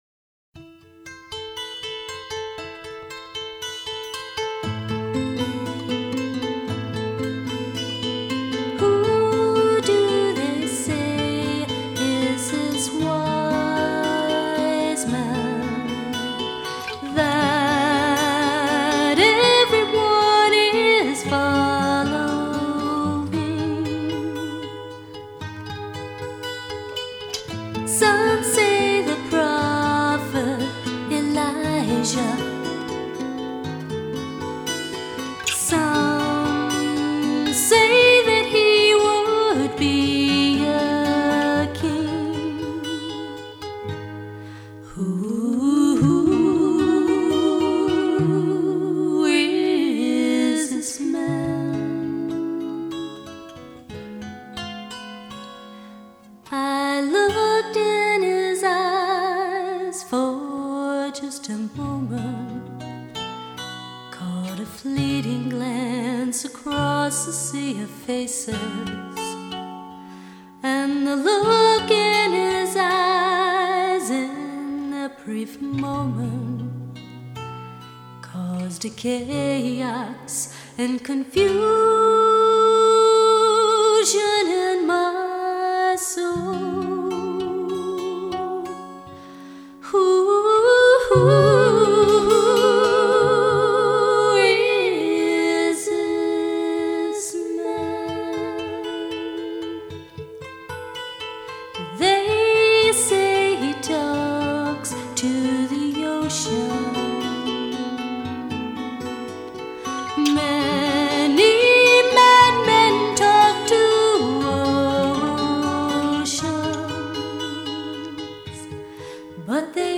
free christian music download
on lead guitar